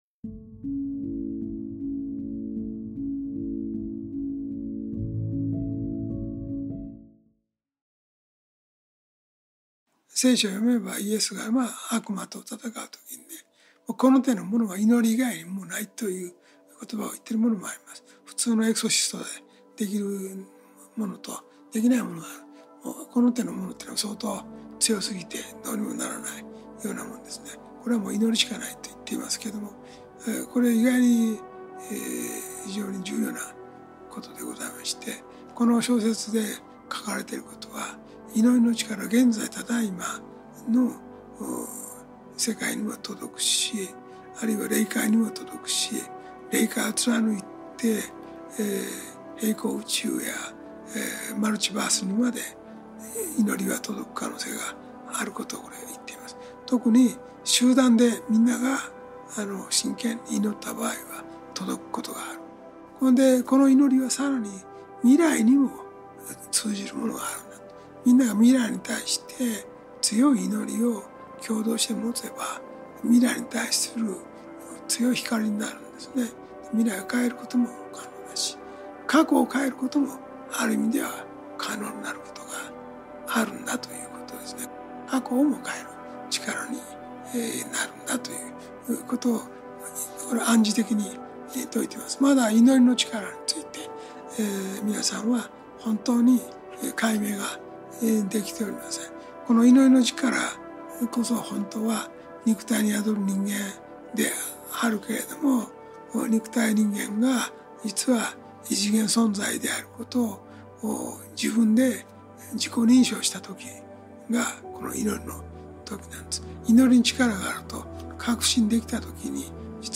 ラジオ番組「天使のモーニングコール」で過去に放送された、幸福の科学 大川隆法総裁の説法集です。